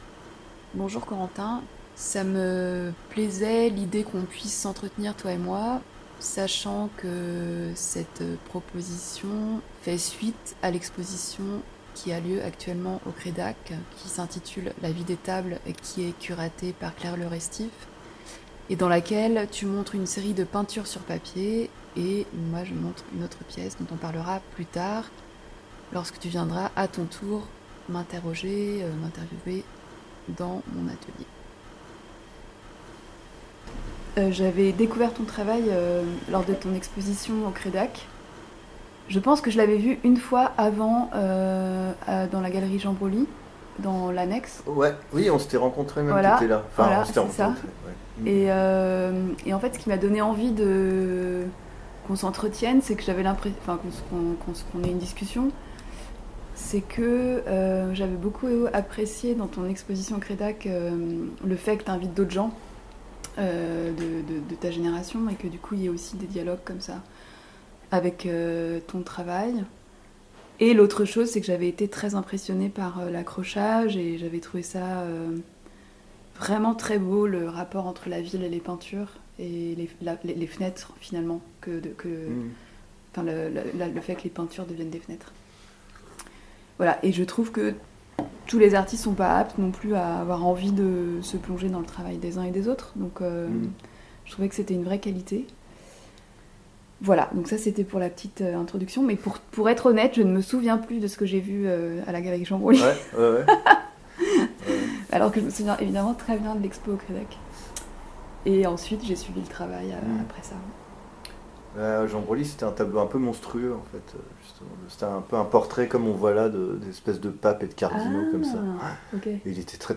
Entretien croisé nº2